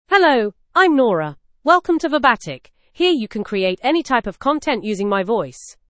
FemaleEnglish (United Kingdom)
Nora is a female AI voice for English (United Kingdom).
Voice: NoraGender: FemaleLanguage: English (United Kingdom)ID: nora-en-gb
Voice sample
Nora delivers clear pronunciation with authentic United Kingdom English intonation, making your content sound professionally produced.